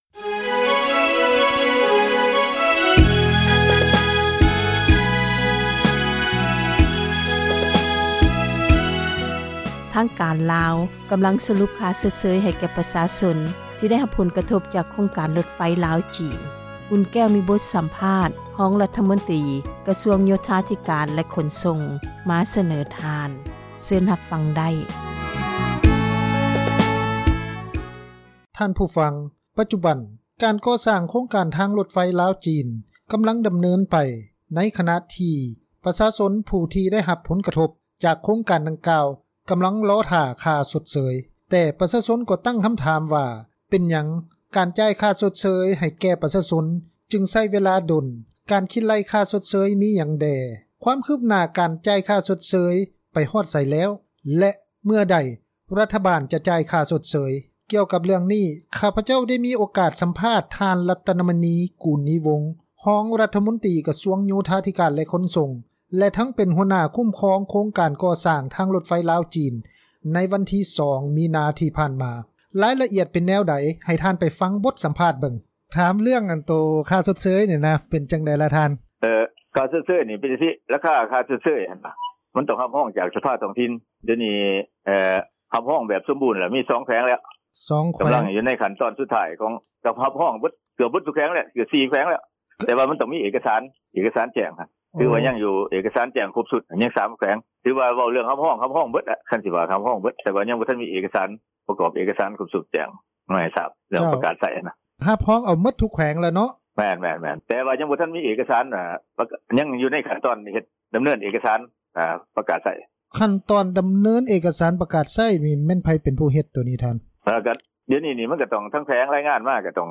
ສໍາພາດ ຮອງຣັຖມົນຕຼີ ກະຊວງ ໂຍທາທິການ ແລະ ຂົນສົ່ງ
ກ່ຽວກັບເລື້ອງນີ້, ຂ້າພະເຈົ້າໄດ້ມີໂອກາດ ສໍາພາດ ທ່ານ ລັດຕະນະມະນີ ຄູນນີວົງ ຮອງຣັຖມົນຕຼີ ກະຊວງໂຍທາທິການ ແລະຂົນສົ່ງ ແລະ ທັງເປັນຫົວໜ້າຄຸ້ມຄອງ ໂຄງການກໍ່ສ້າງທາງຣົດໄຟ ລາວ-ຈີນ ໃນວັນທີ 2 ມີນາ ຜ່ານມາ.